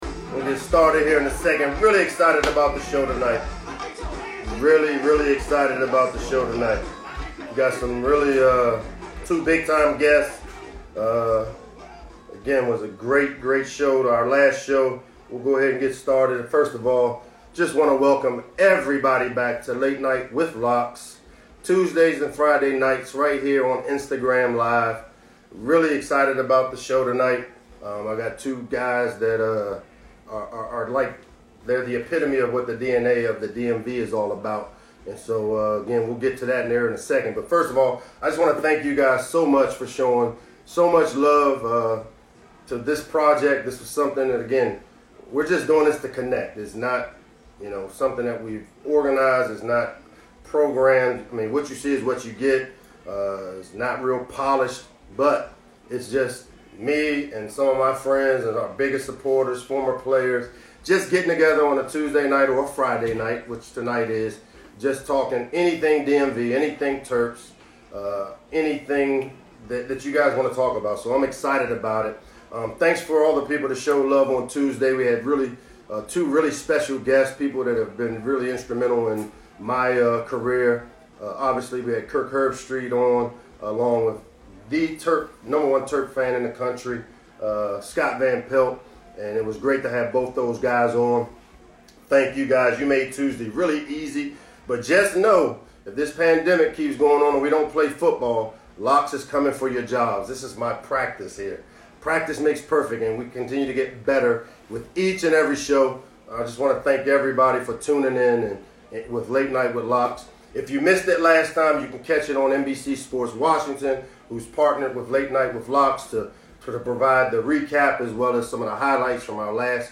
April 19, 2020 Late Night with Locks is an Instagram live show hosted by head football coach Michael Locksley every Tuesday and Friday evening at 7 p.m. This show featured Quinn Cook of the Los Angeles Lakers and Stefon Diggs of the Buffalo Bills.